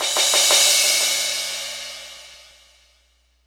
crash01.wav